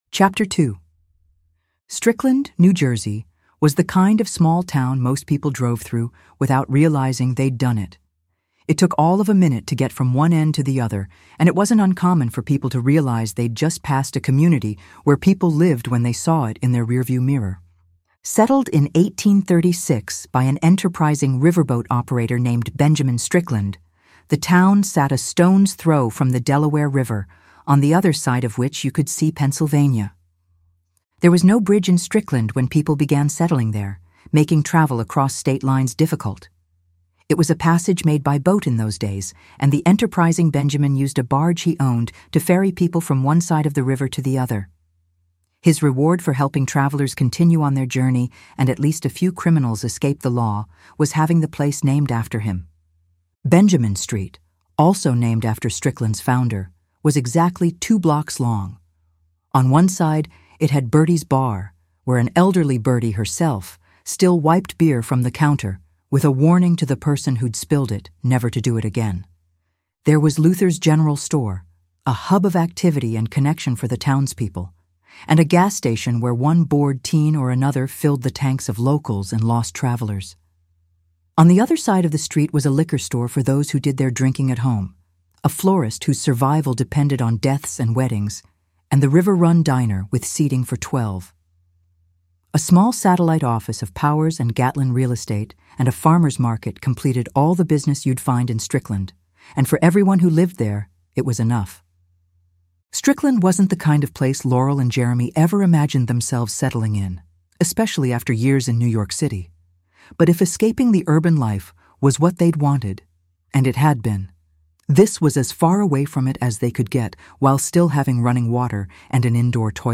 Welcome to the episodic audio edition of A House in the Woods 2: The Devil’s Due.